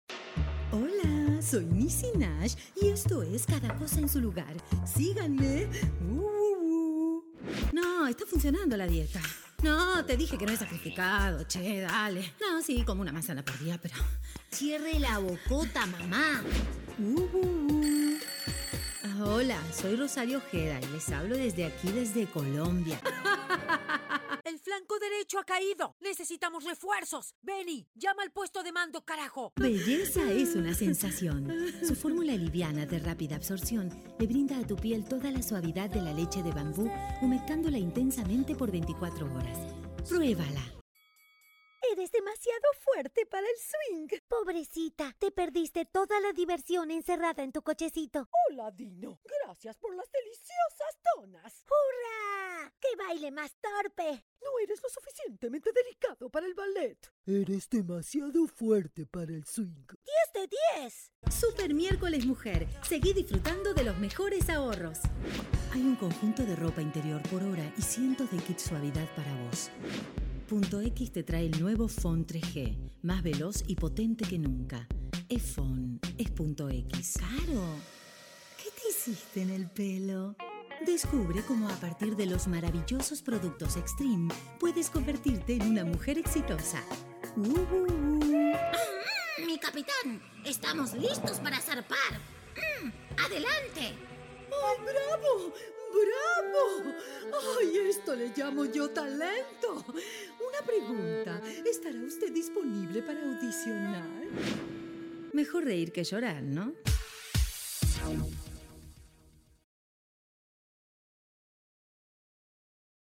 Female
Character / Cartoon
Versatilidad vocal artística completa: cartoon, videojuegos, animación, comercial.
Caracterización múltiple con actuación expresiva y producción musical.
0930CARTOON_COMERCIAL_VIDEOJUEGOS.mp3